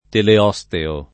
[ tele 0S teo ]